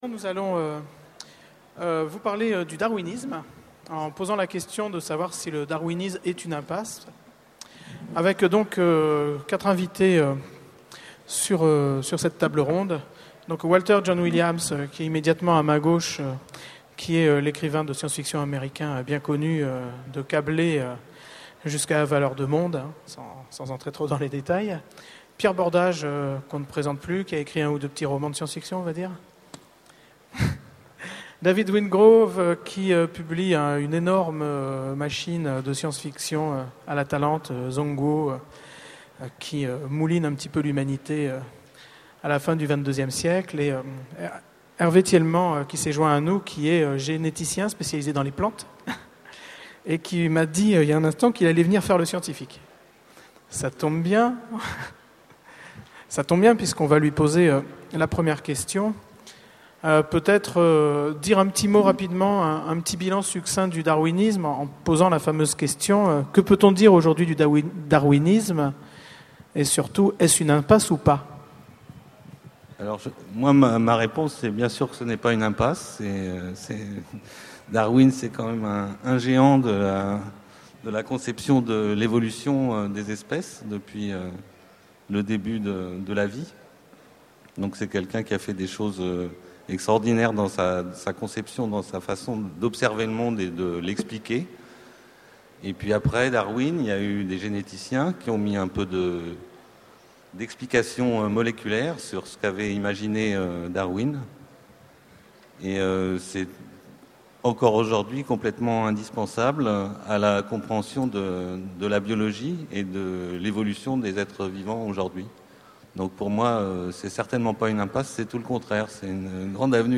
Voici l'enregistrement de la conférence "Darwin, une impasse ?" aux Utopiales 2009.